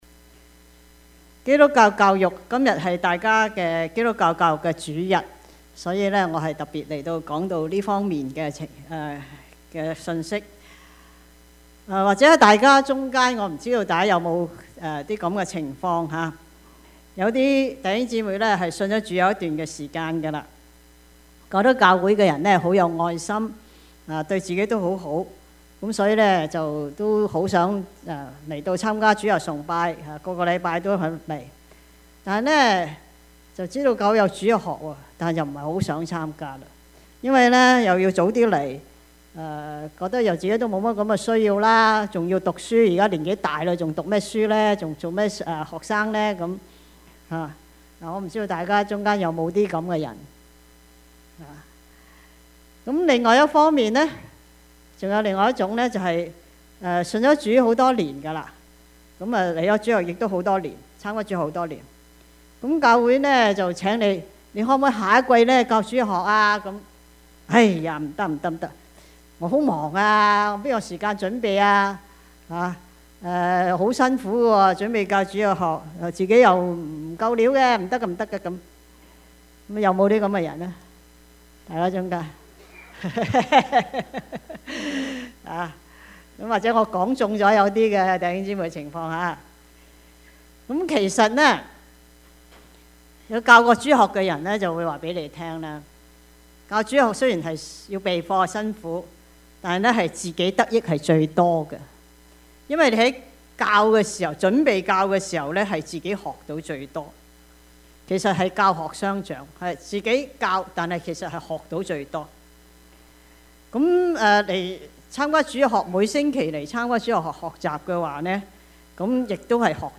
Service Type: 主日崇拜
Topics: 主日證道 « 生之盼 長征 (4) »